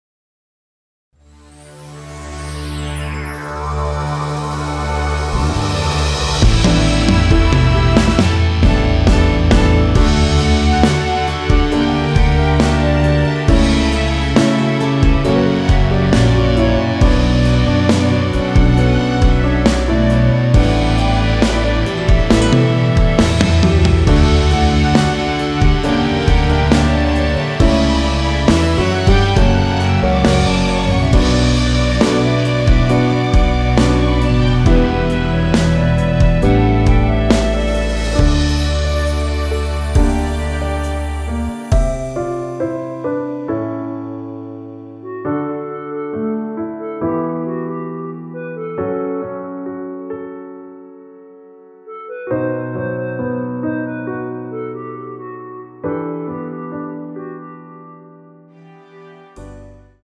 원키 멜로디 포함된 MR입니다.(미리듣기 확인)
앞부분30초, 뒷부분30초씩 편집해서 올려 드리고 있습니다.
중간에 음이 끈어지고 다시 나오는 이유는